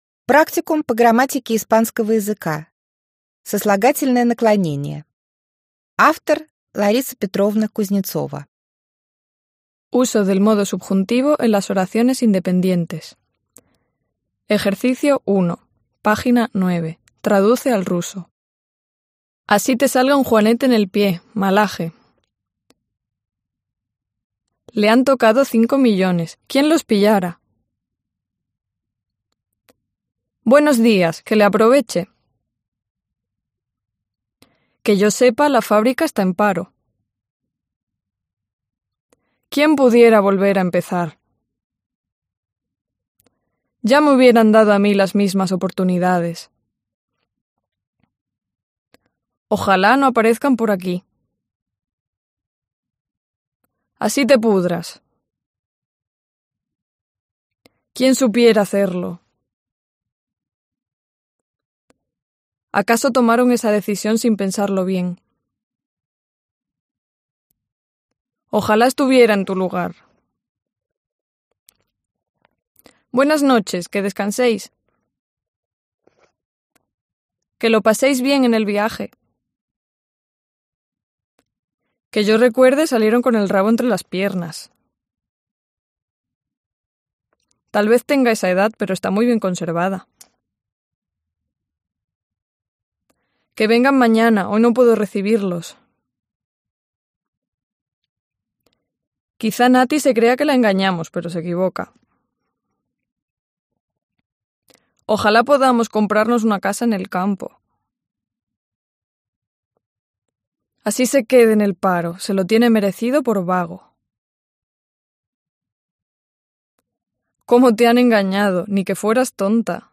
Аудиокнига Практикум по грамматике испанского языка. Сослагательное наклонение | Библиотека аудиокниг